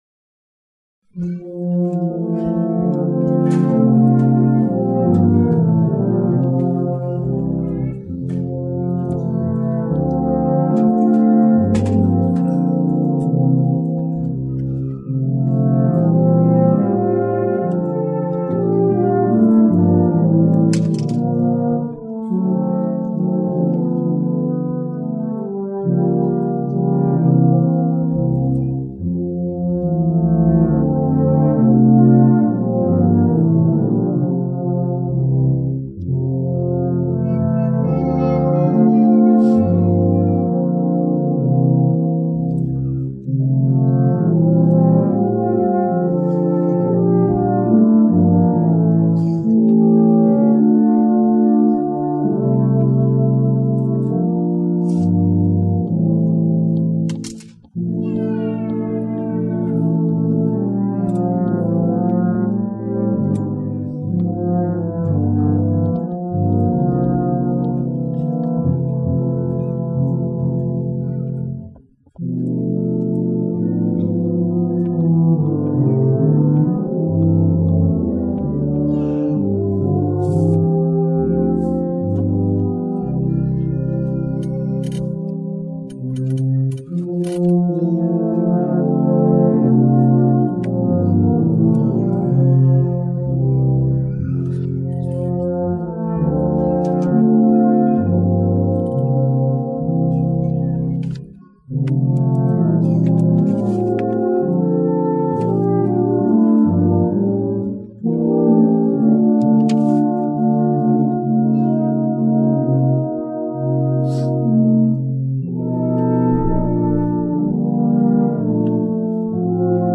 The sound quality is not the best since I was sitting 5 rows behind them on the stage, but it should give you a good idea.
The Tubadours also performed and sounded great...
Have_Yourself_A_Merry_Little_Christmas-Tubadours2006.mp3